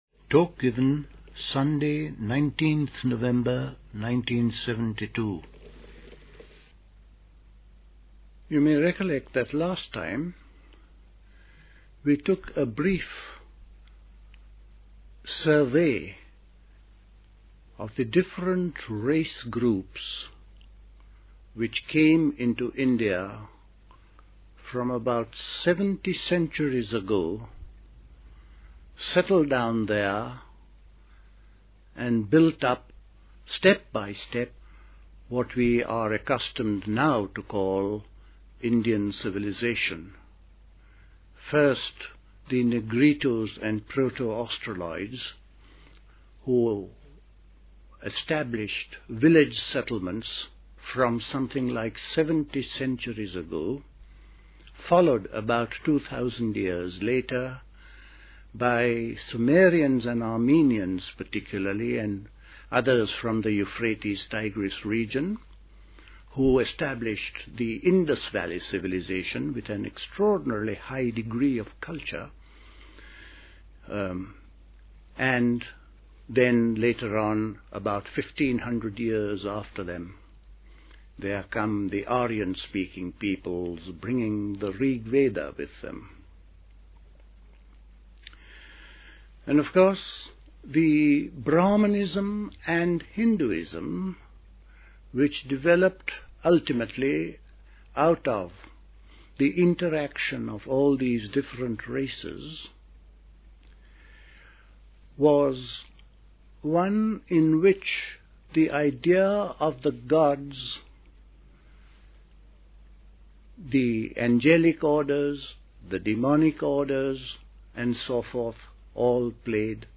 A talk
at Dilkusha, Forest Hill, London on 19th November 1972